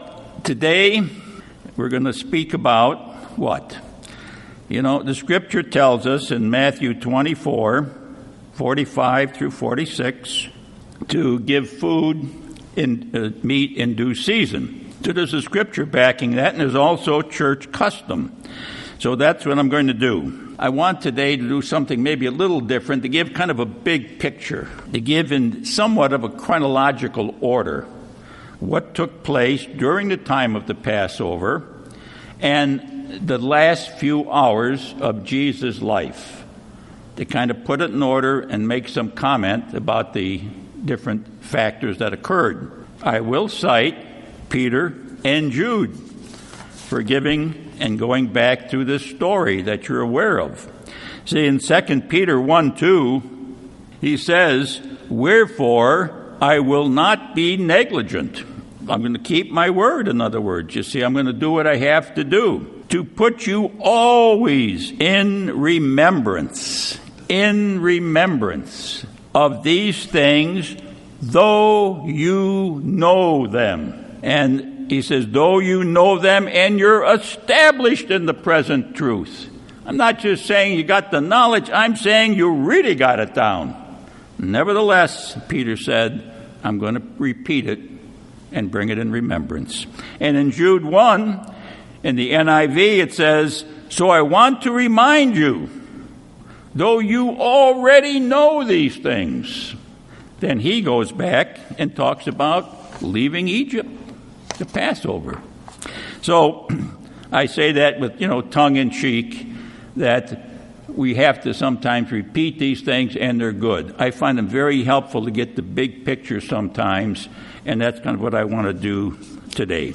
Given in Redlands, CA